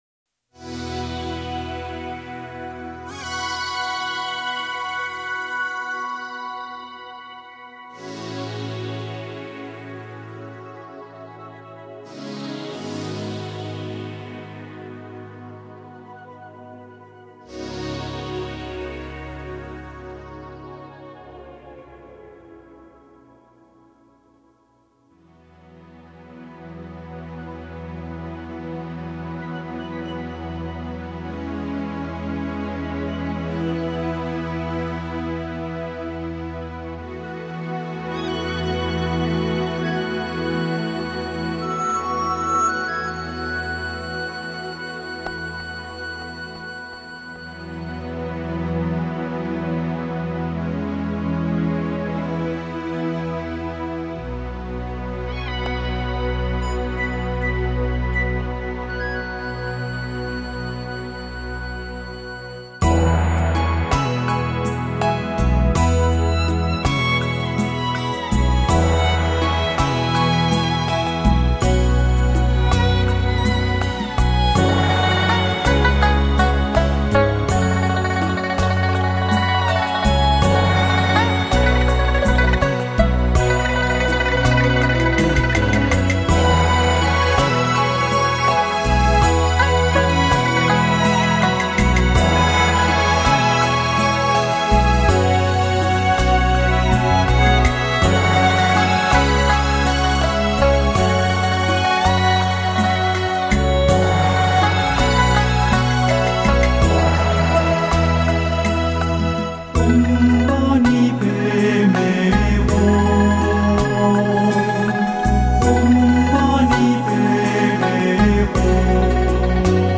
[15/1/2010]六字大明咒-唵嘛呢叭咪吽 [男声版]